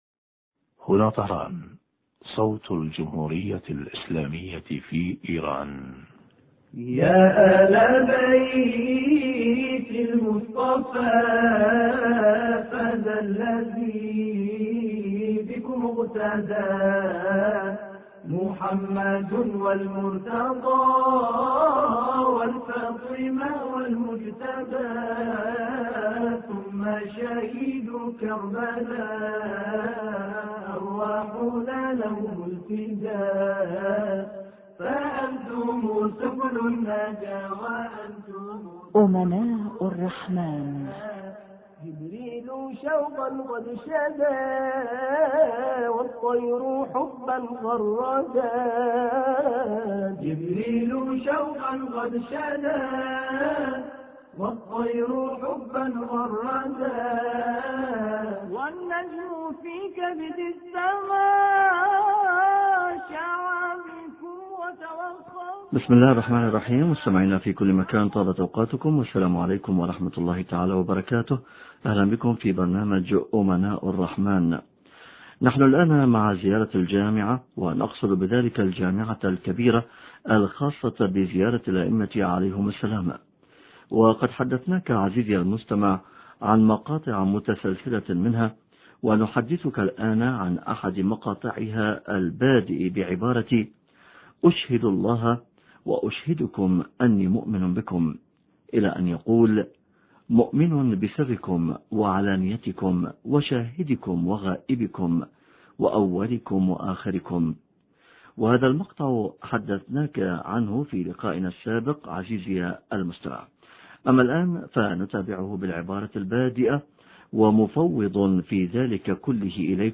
شرح فقرة: ومفوض في ذلك كله اليكم ومسلم فيه معكم... حوار